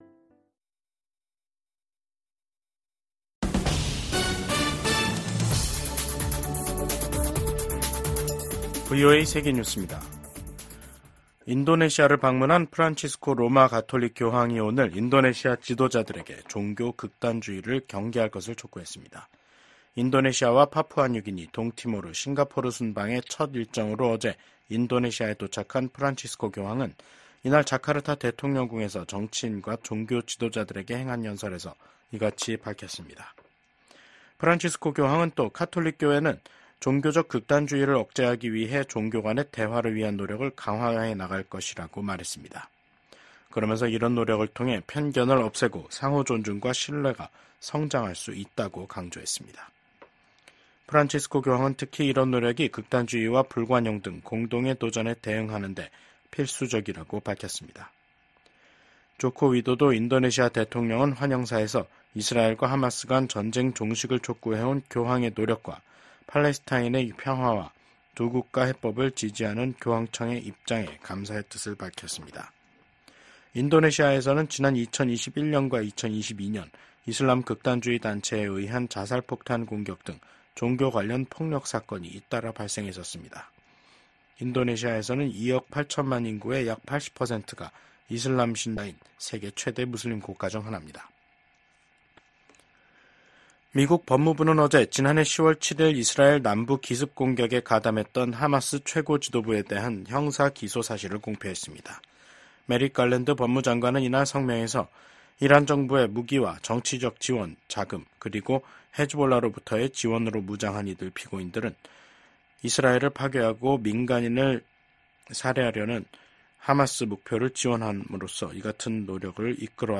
VOA 한국어 간판 뉴스 프로그램 '뉴스 투데이', 2024년 9월 4일 2부 방송입니다. 미국 국무부가 한국 정부의 대북 라디오 방송 지원 방침을 지지한다는 입장을 밝혔습니다. 기시다 후미오 일본 총리가 퇴임을 앞두고 한국을 방문해 윤석열 대통령과 회담을 합니다. 북한 열병식 훈련장 인근에 버스로 추정되는 차량 수십 대가 집결했습니다.